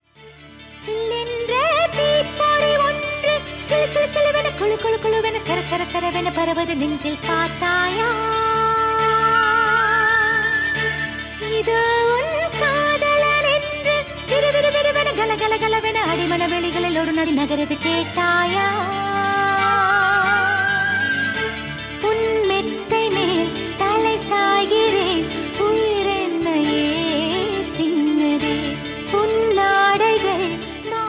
tamil ringtonekollywood ringtonemass ringtonemobile ringtone
best flute ringtone download